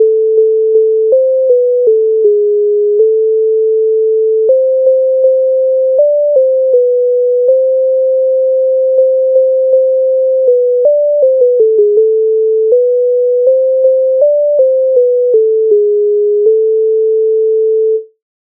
Нова радість Українська народна пісня колядка
Ukrainska_narodna_pisnia_Nova_radist.mp3